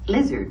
lizard.wav